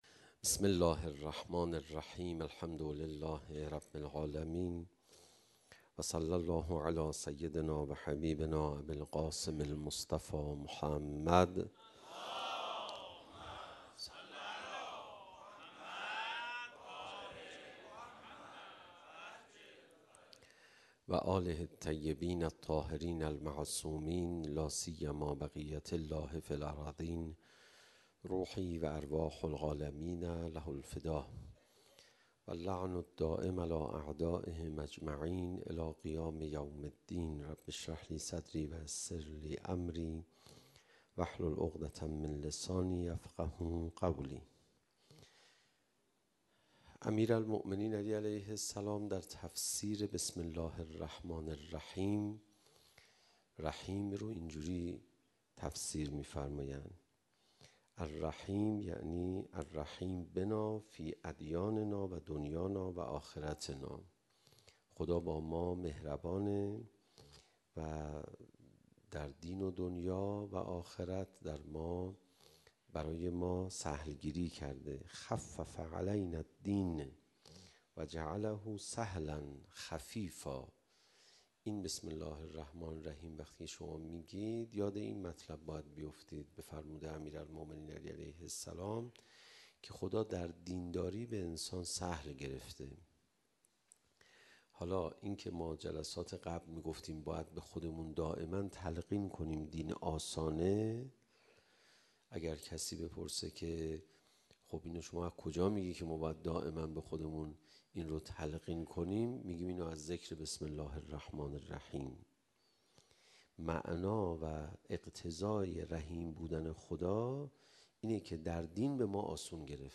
فاطمیه 97 - حسینیه حق شناس - روز پنجم